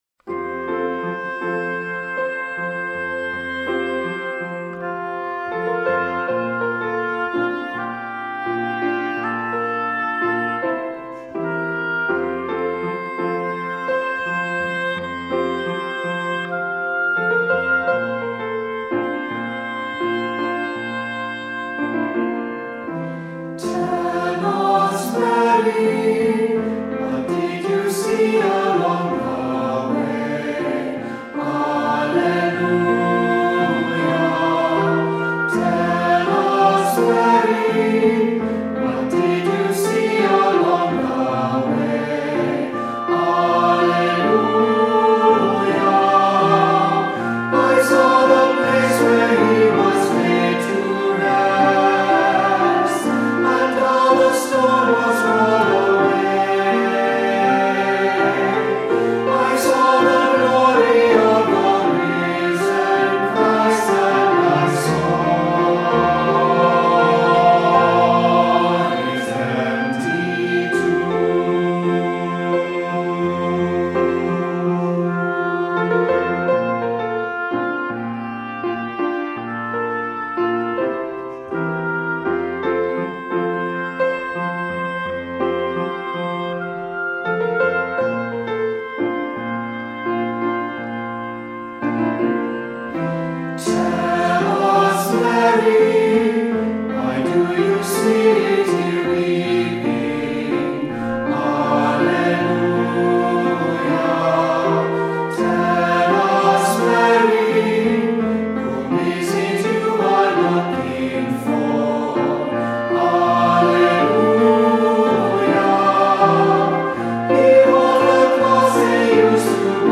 Voicing: Unison; Adults with children; Assembly